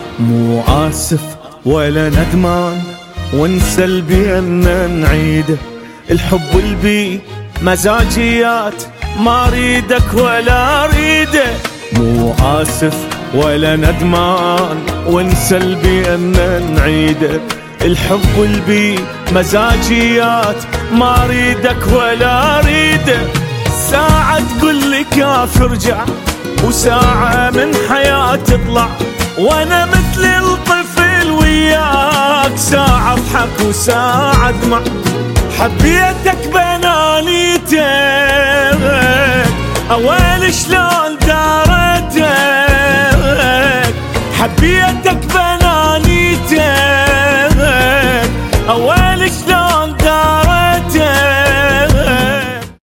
صوت قوي محلاه 😍